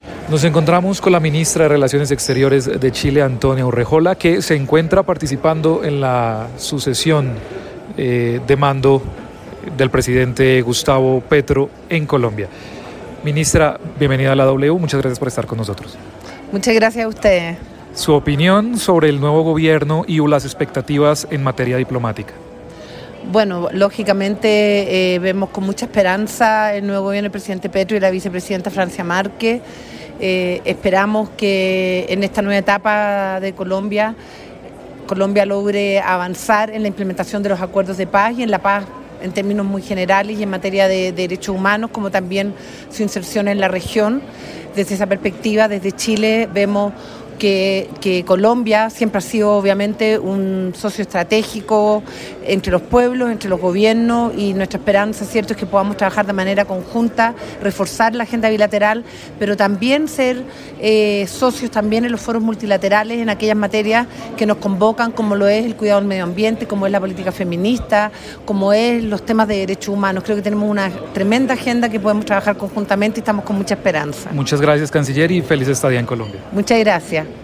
Apropósito de la posesión de Gustavo Petro como presidente de Colombia, W Radio conversó con Antonia Urrejola, la ministra de Relaciones Exteriores de Chile, sobre los principales desafíos en la región.
En el encabezado escuche las declaraciones de Antonia Urrejola, la canciller de Chile.